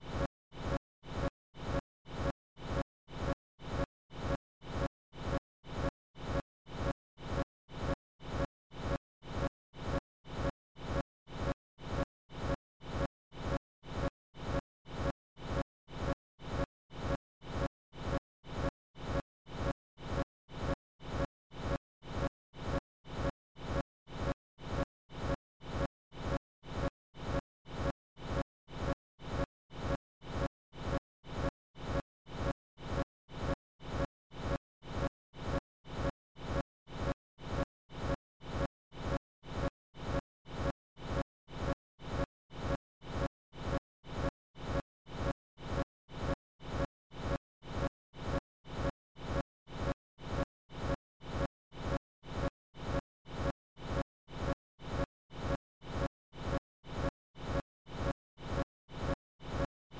Voice Samples: HPB Health Hub
EN Asian